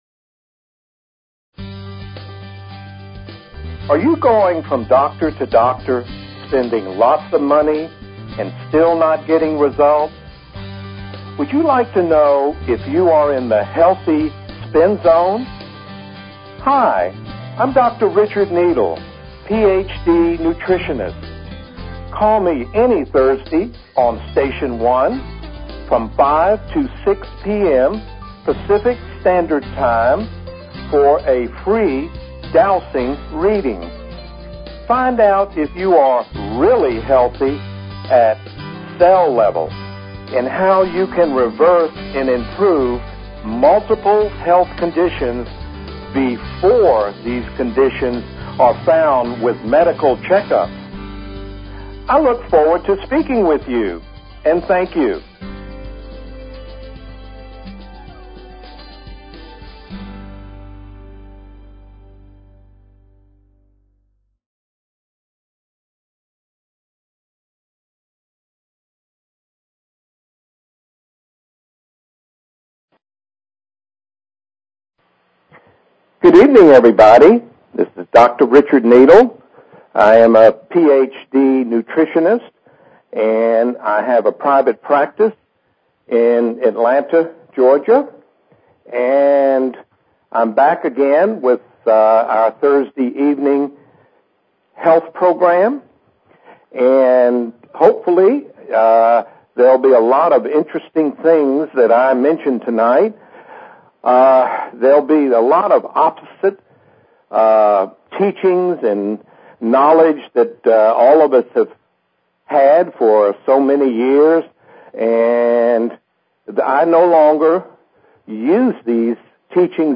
Talk Show Episode, Audio Podcast, Dowsing_for_Health and Courtesy of BBS Radio on , show guests , about , categorized as